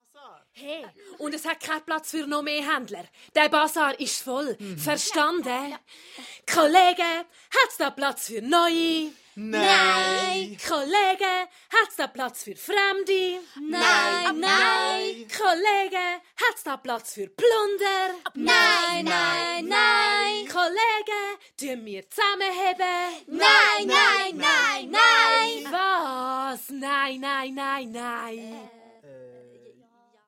Ravensburger Ladina und die Plunderlampe ✔ tiptoi® Hörbuch ab 4 Jahren ✔ Jetzt online herunterladen!